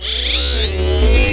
Added optional "dummied out" Sniper zoom sounds from a dubious source. 2019-09-28 20:06:51 +02:00 13 KiB Raw History